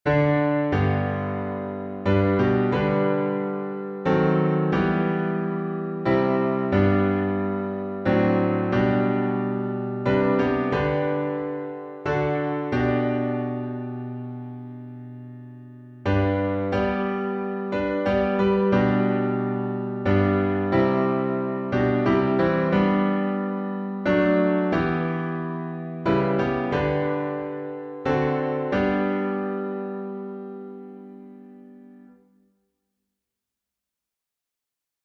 Amazing Grace — alternate chording.